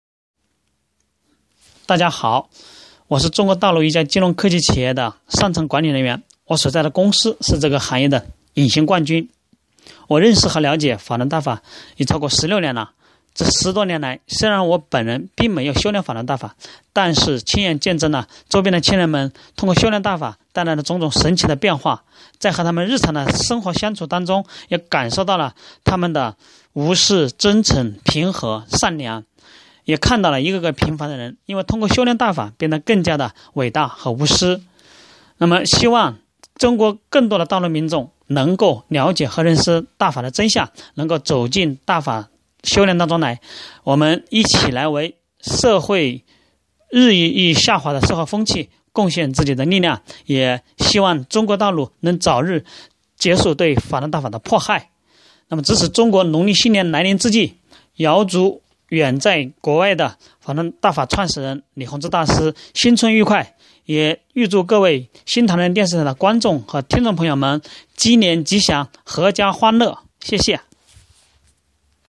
Tonaufnahme aus dem Bankwesen: